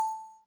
notification-2.mp3